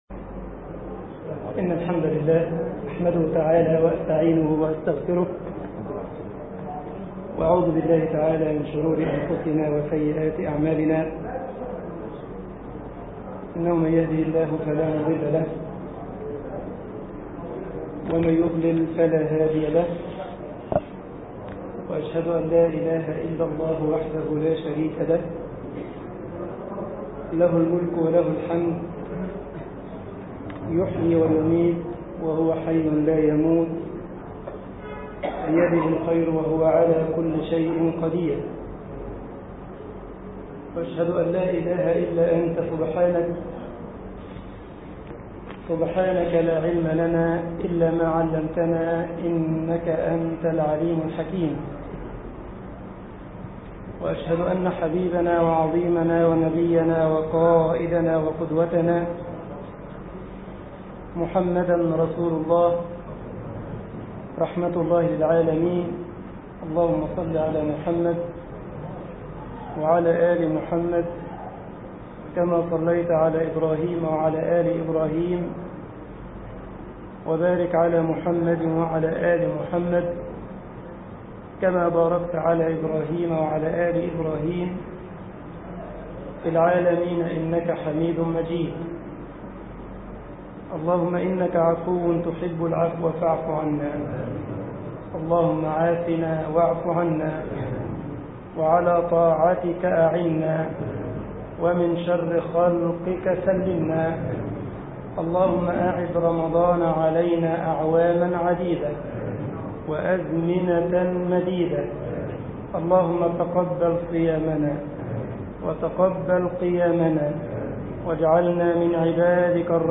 مسجد غمرة المنوفي ـ الشرابية ـ القاهرة